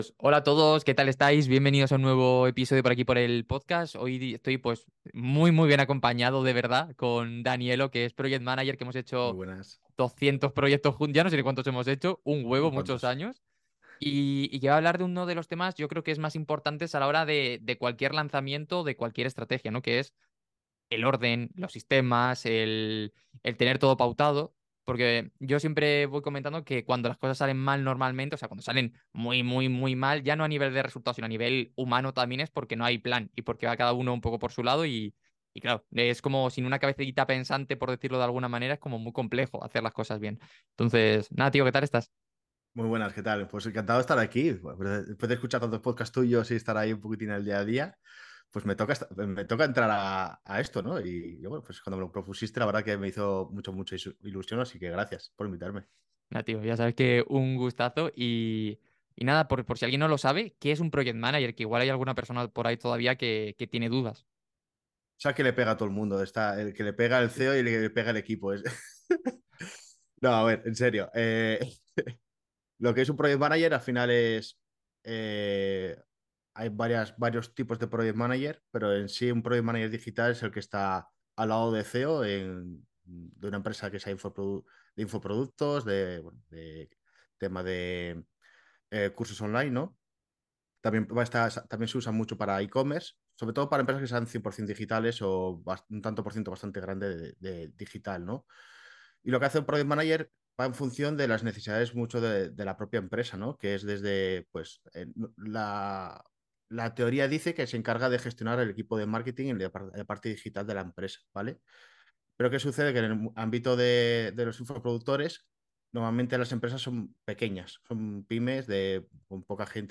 Hoy tengo el placer de entrevistar a una de las mejores personas que he encontrado en el mundo de los negocios digitales.